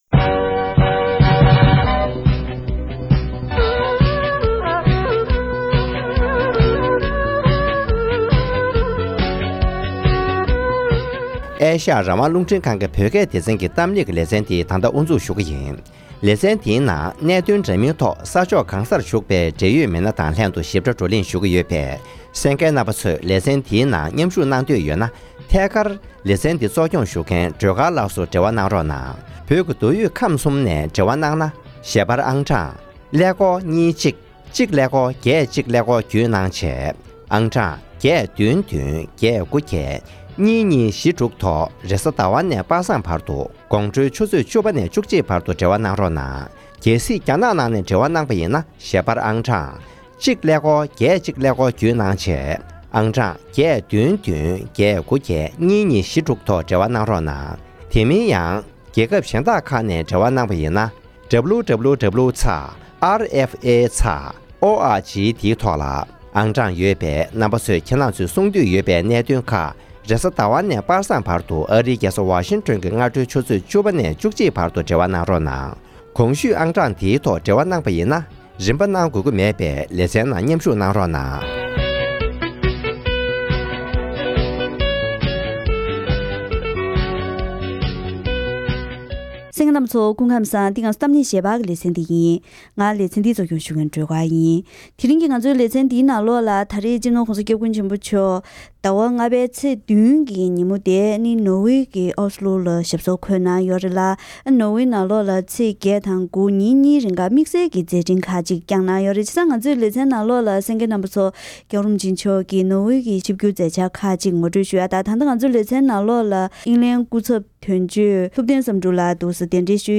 ༄༅༎ཐེངས་འདིའི་གཏམ་གླེང་ཞལ་པར་གྱི་ལེ་ཚན་ནང་དུ། སྤྱི་ནོར་༧གོང་ས་སྐྱབས་མགོན་ཆེན་པོ་མཆོག་ ནོར་བེ་ནང་ཞབས་སོར་འཁོད་ནས་མཛད་འཕྲིན་ཁག་བསྐྱངས་ཡོད་པས། ཞིབ་ཕྲའི་མཛད་འཆར་ཁག་གི་ངོ་སྤྲོད་དང་། དམིགས་བསལ་ཚེས་༩ཉིན་ནོར་བེ་གྲོས་ཚོགས་སུ་ཆིབས་བསྒྱུར་གྱིས་གྲོས་ཚོགས་འཐུས་མིས་མཇལ་ཁ་ཞུས་པའི་ཁྲོད་ནས་གསར་འགྱུར་ནང་ཐོན་པའི་ནོར་བེ་གཞུང་གི་དཔོན་རིགས་ཀྱིས་མཇལ་ཁ་ཞུས་མེད་པའི་གནད་དོན་ཐོག་འབྲེལ་ཡོད་དང་ལྷན་བཀའ་མོལ་ཞུས་པ་ཞིག་གསན་རོགས༎